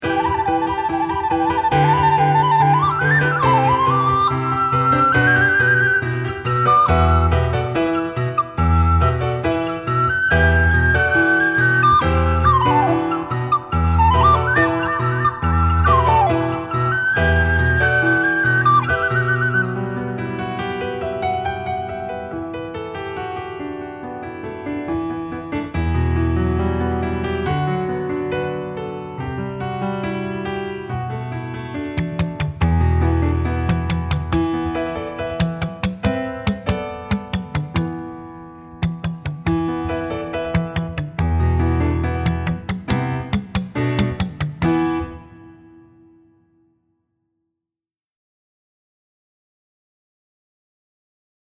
Blockflöte
Solovioline
Gitarre
Violoncello